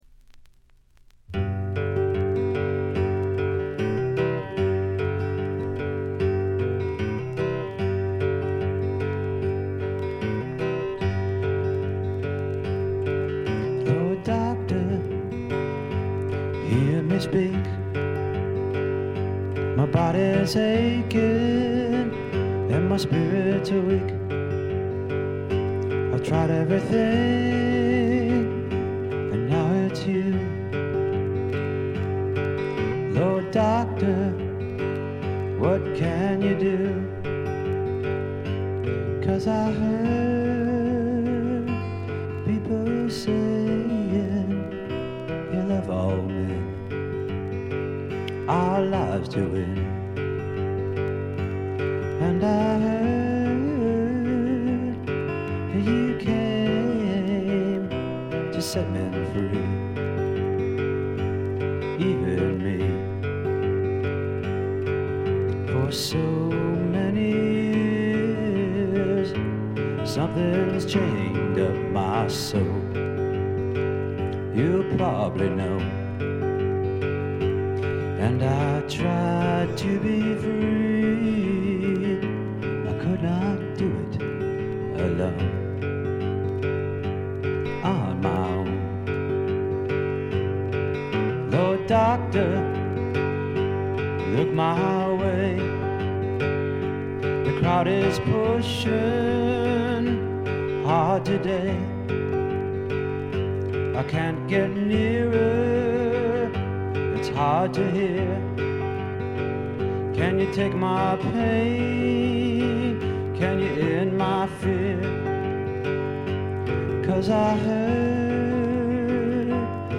静音部での微細なチリプチ程度。
試聴曲は現品からの取り込み音源です。